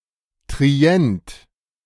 Trento (Italian: [ˈtrento] or [ˈtrɛnto];[3] Ladin and Lombard: Trent; German: Trient [tʁiˈɛnt]
De-Trient.ogg.mp3